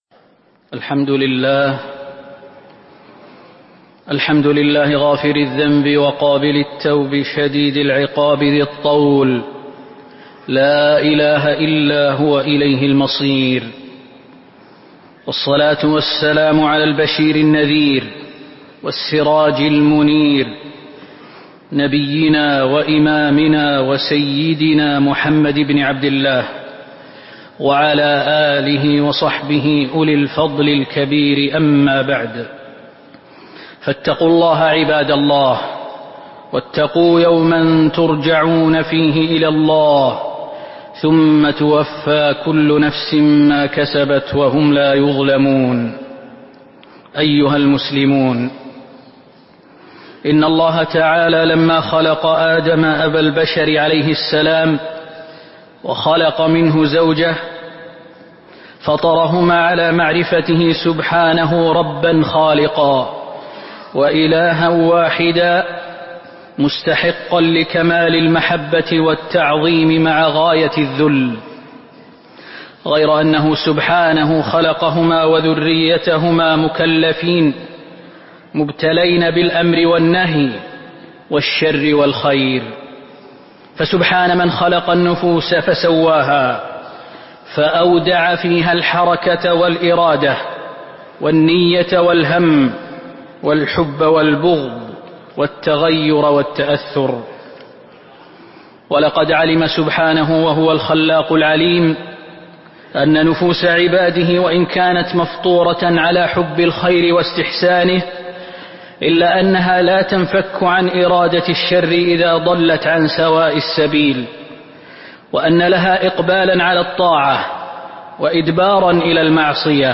تاريخ النشر ١٥ شعبان ١٤٤٦ هـ المكان: المسجد النبوي الشيخ: فضيلة الشيخ د. خالد بن سليمان المهنا فضيلة الشيخ د. خالد بن سليمان المهنا فضائل الاستغفار